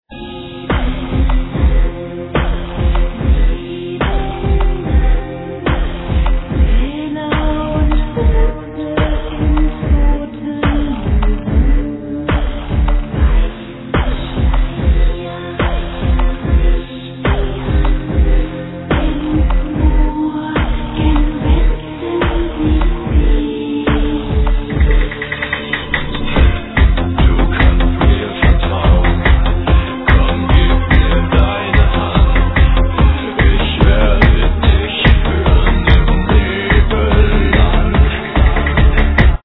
Vocals, Programming
Vocals